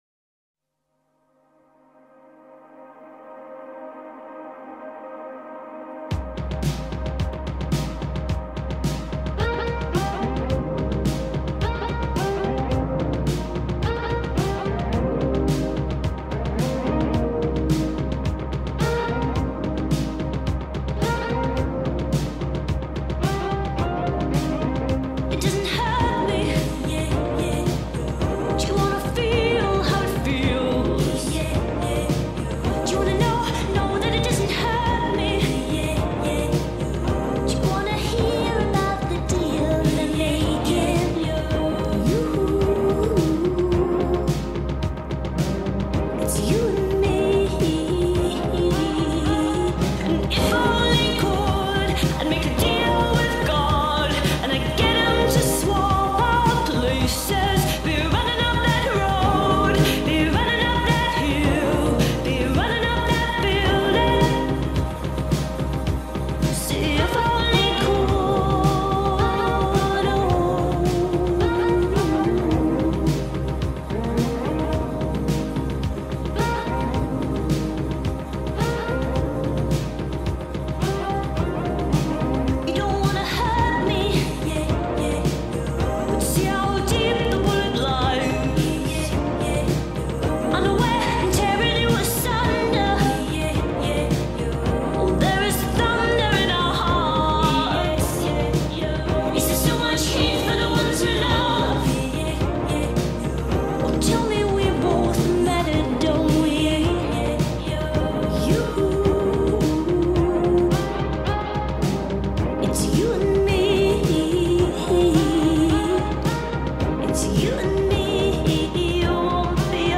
غمگین